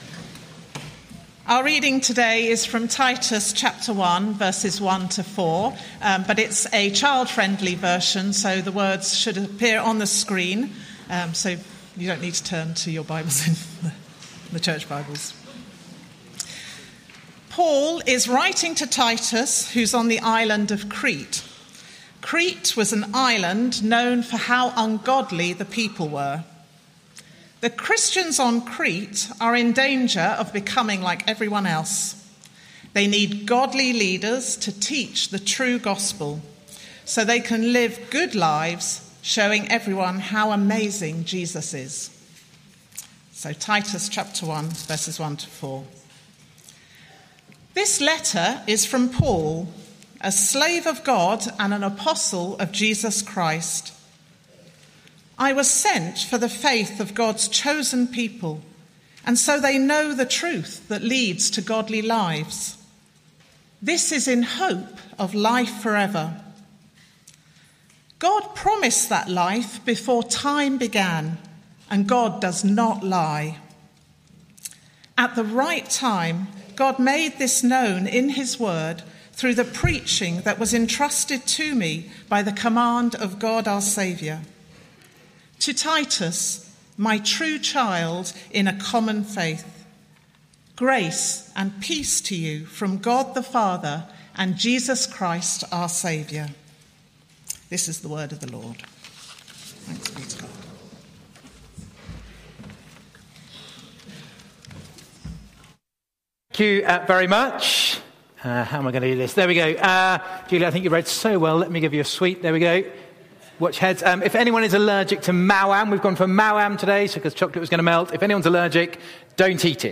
Media for Morning Meeting on Sun 22nd Jun 2025 10:30 Speaker
AM Theme: Sermon Search media library...